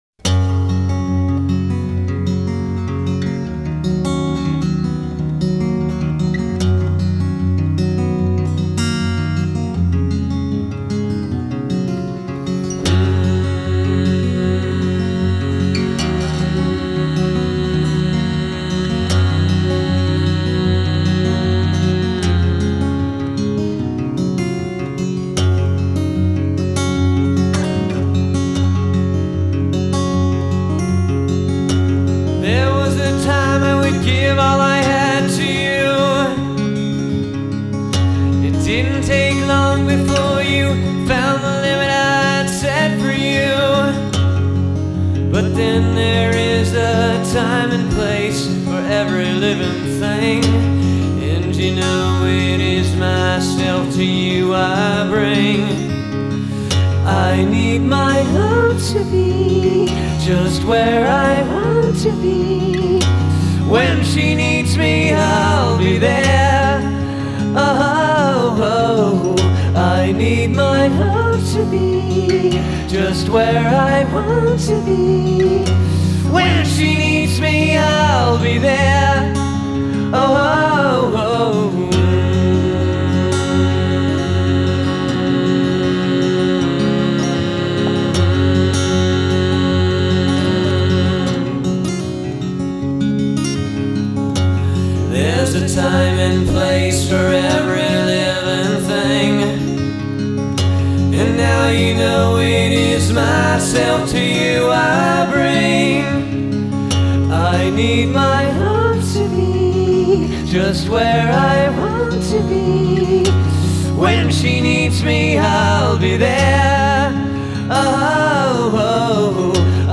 early-Beatles-sounding